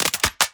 GUNMech_Insert Clip_04_SFRMS_SCIWPNS.wav